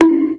bonk.ogg